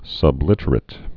(sŭb-lĭtər-ĭt)